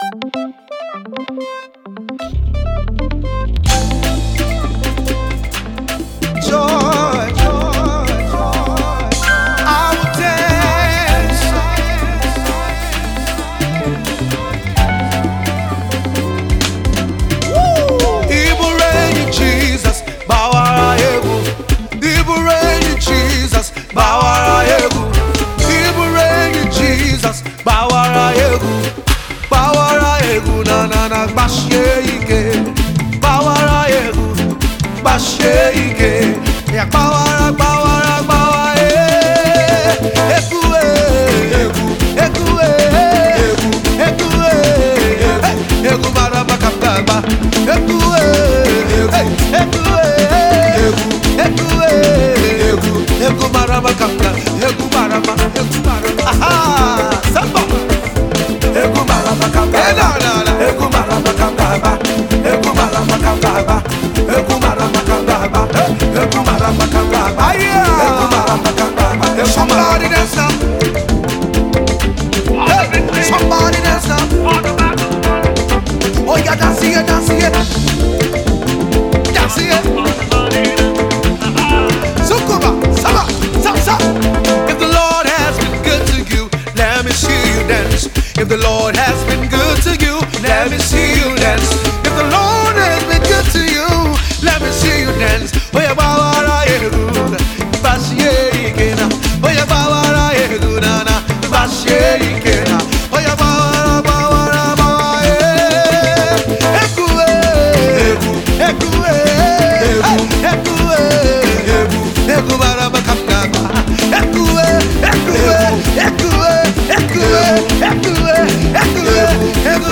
groovy African praise jam
the addictive uptempo is delivered partly in two languages